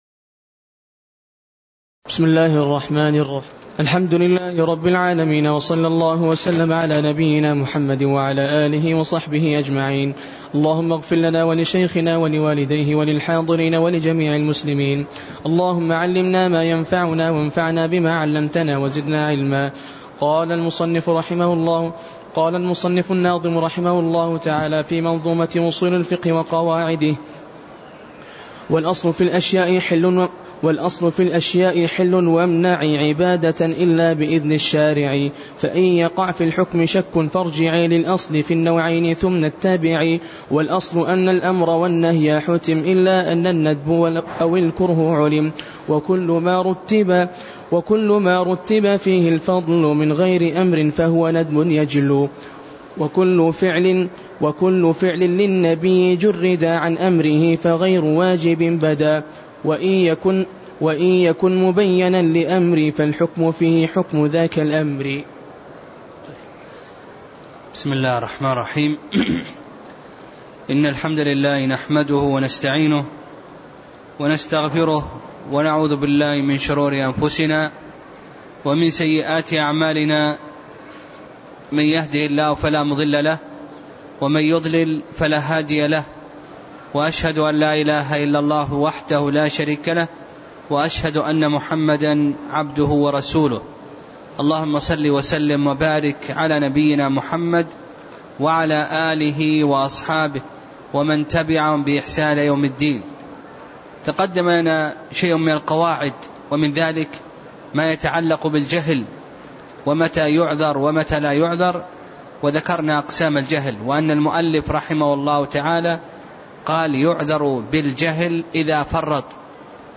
تاريخ النشر ٢ شعبان ١٤٣٤ هـ المكان: المسجد النبوي الشيخ